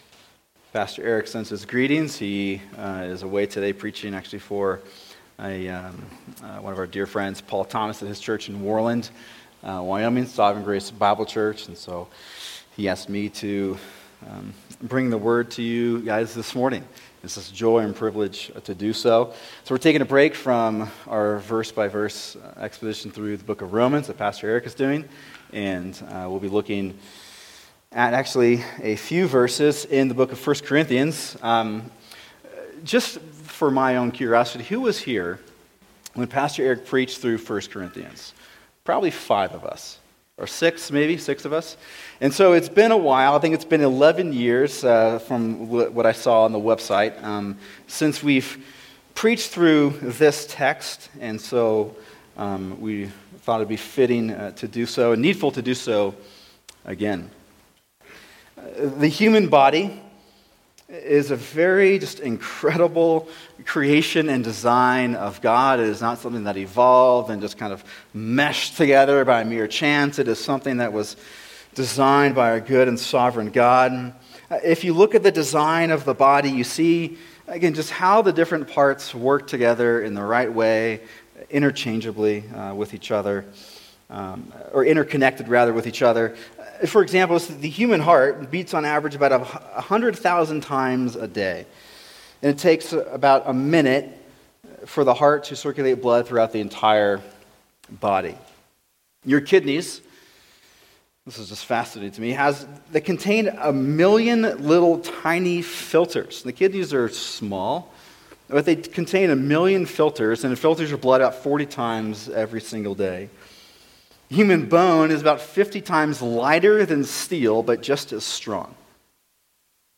[sermon] 1 Corinthians 12:12-27 How to be a Faithful Body Part | Cornerstone Church - Jackson Hole